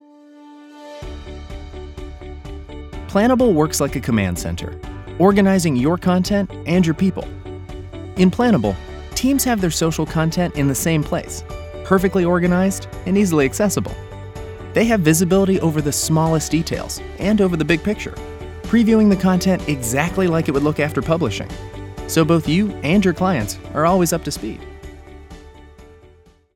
englisch (us)
Sprechprobe: Industrie (Muttersprache):